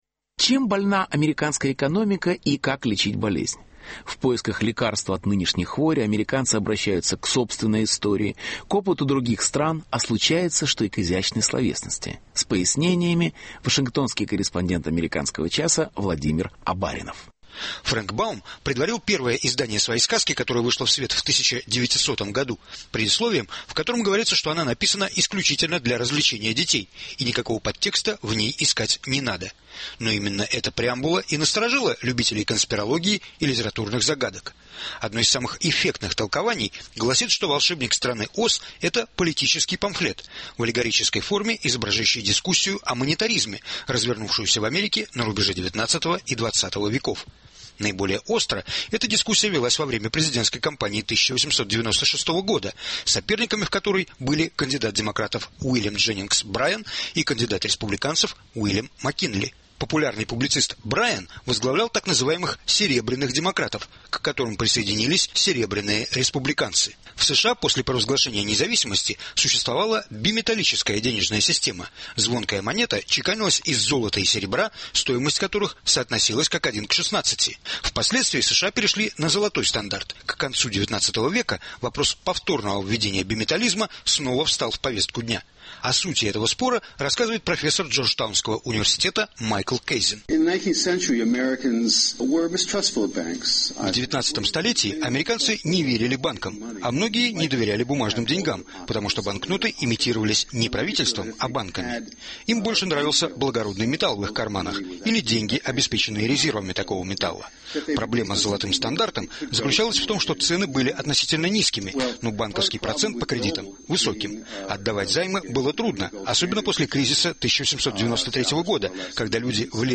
Репортаж. В Страну Оз за финансовой консультацией.